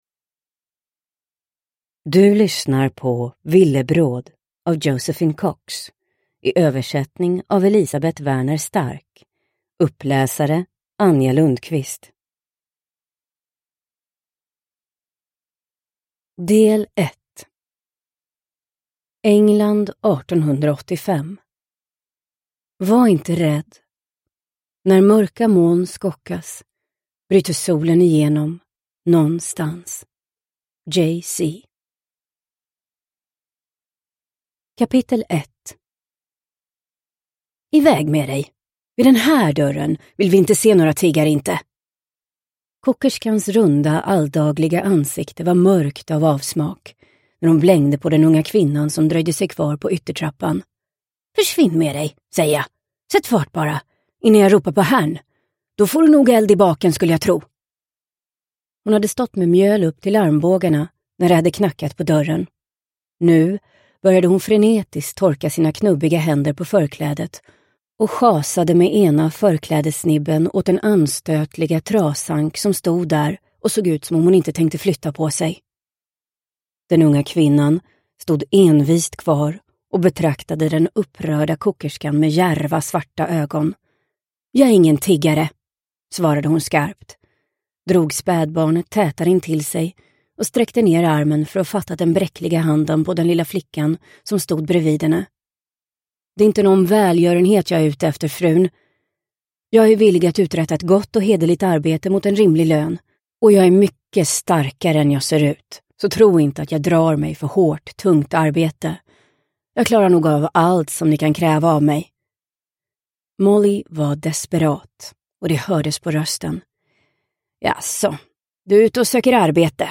Villebråd – Ljudbok